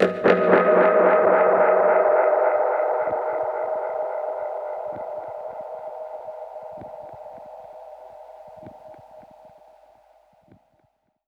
Index of /musicradar/dub-percussion-samples/85bpm
DPFX_PercHit_A_85-08.wav